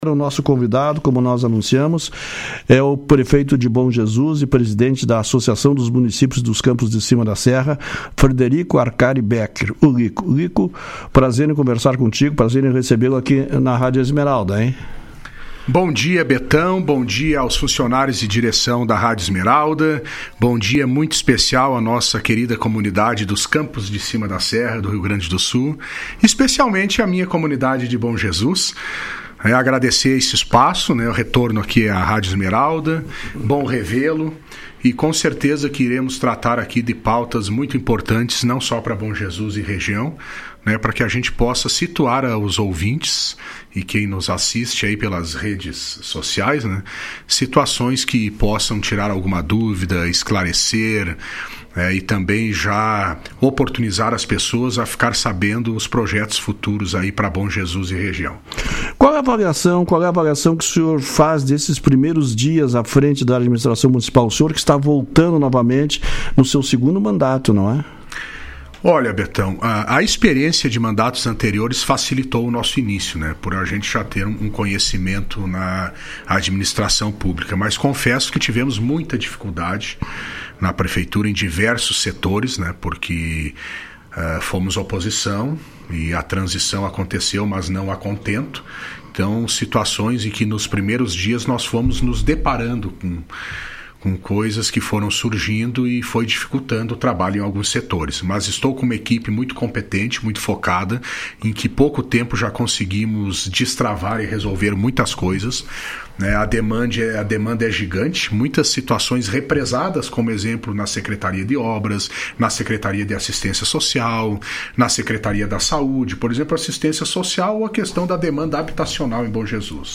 O prefeito de Bom Jesus Frederico Arcari Becker (Progressitas) participou do programa Comando Geral na última sexta-feira. Durante a entrevista ele destacou as primeiras ações de seu governo, em várias secretarias da prefeitura.